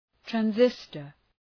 Προφορά
{træn’zıstər}
transistor.mp3